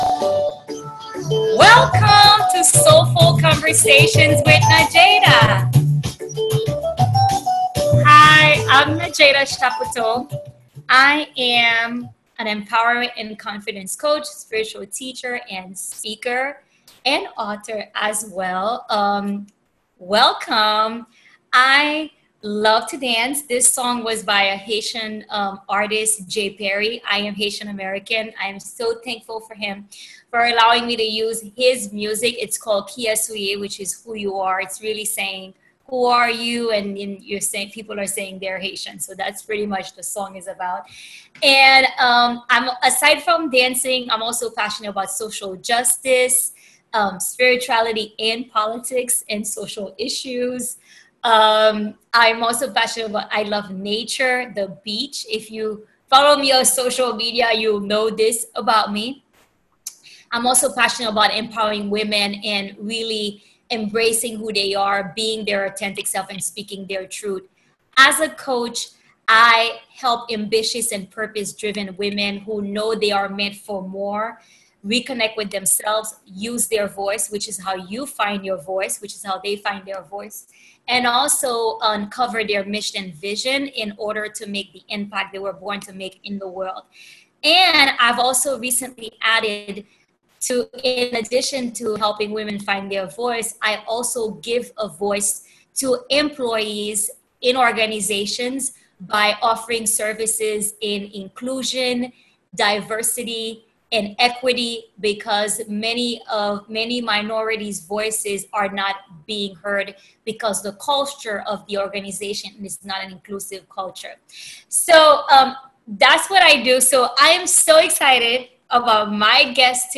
We both got pretty vulnerable sharing ourselves during our recorded conversation.